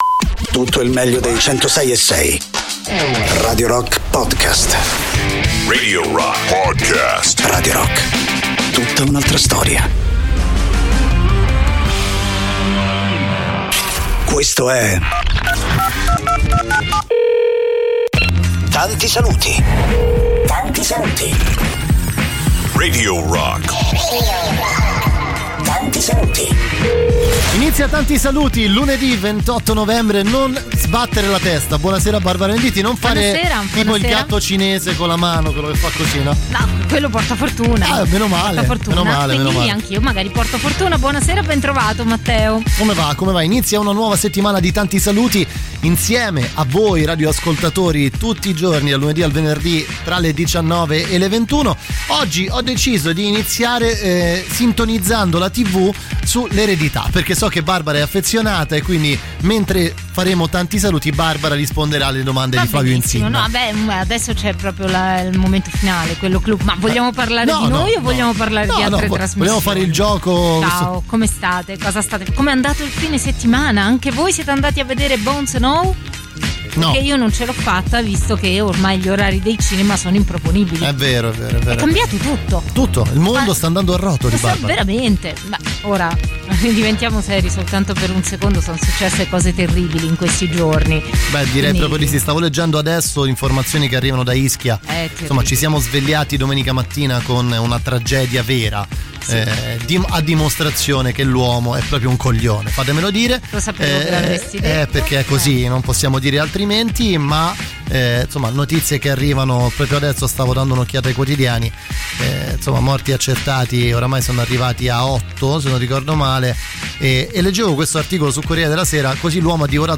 in diretta dal lunedì al venerdì, dalle 19 alle 21